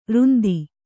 ♪ rundi